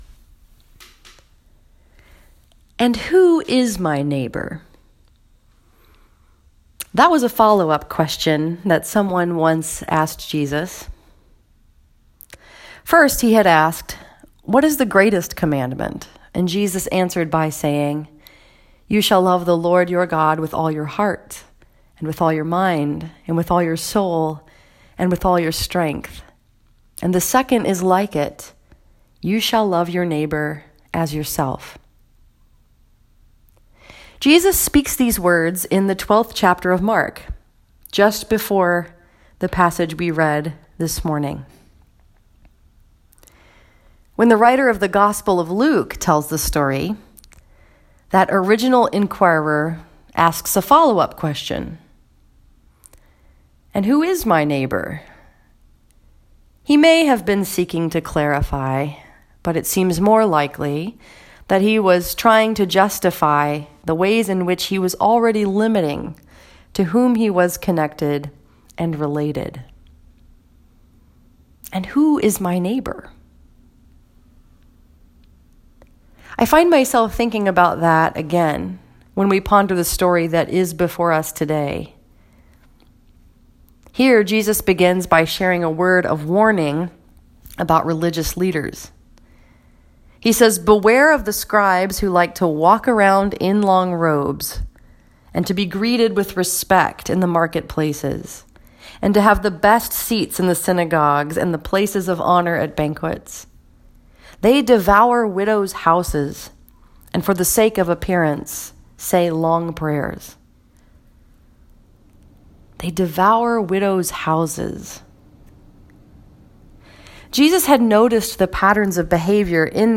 This sermon was preached at First Presbyterian Church in Howell, Michigan and was focused upon the story that is told in Mark 12:38-44.